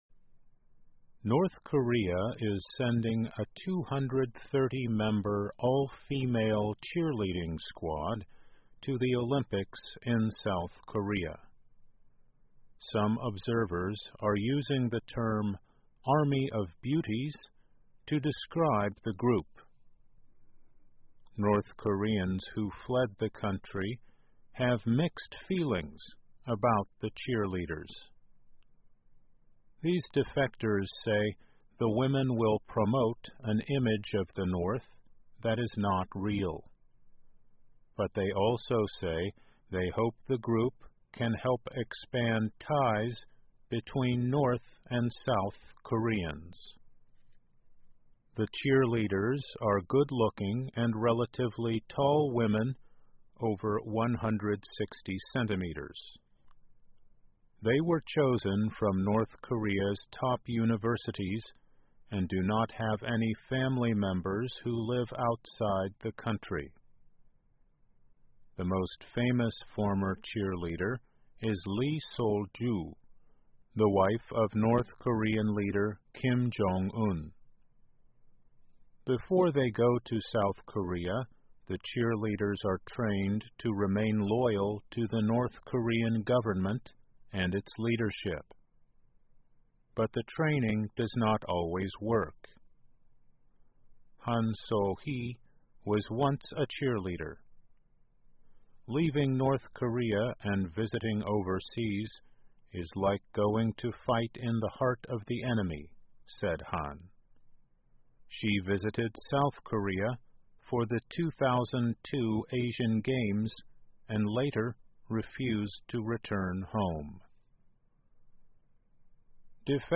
VOA慢速英语2018 朝鲜派“美女军团”拉拉队参加韩国冬奥会 听力文件下载—在线英语听力室